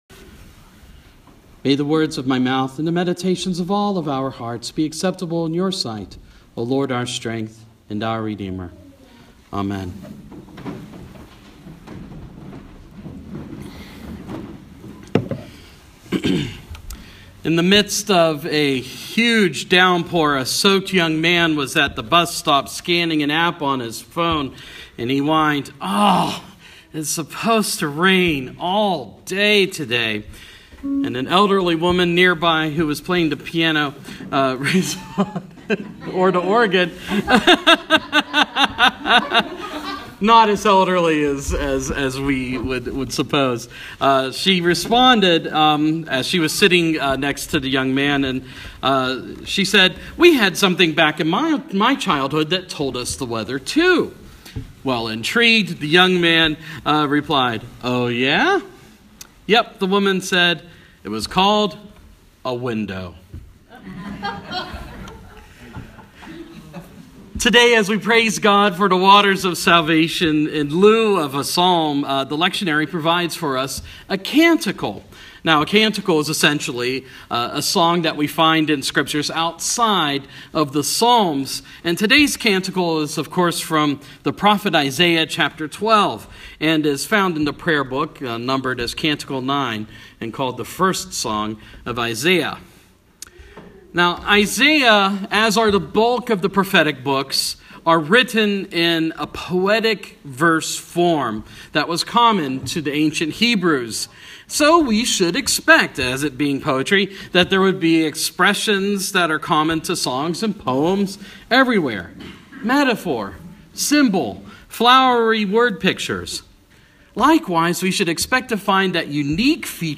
Sermon – Proper 28 – 2016